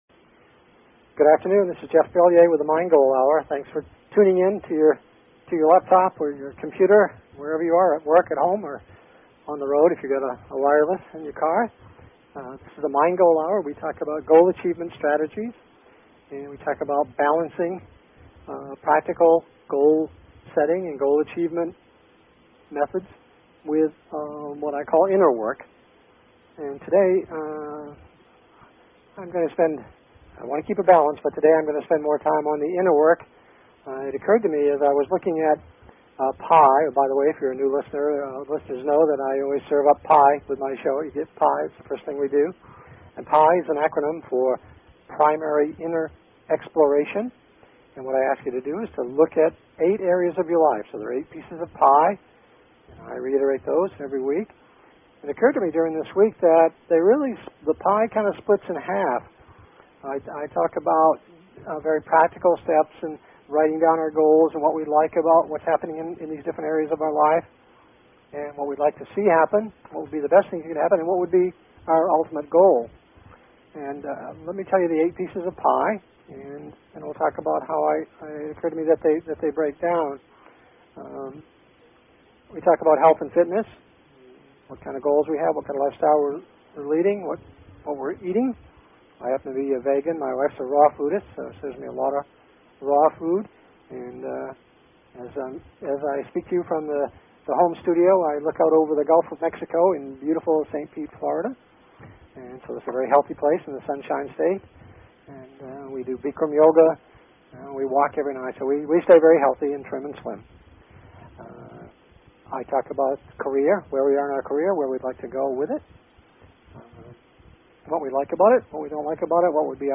Talk Show Episode, Audio Podcast, Mind_Goal and Courtesy of BBS Radio on , show guests , about , categorized as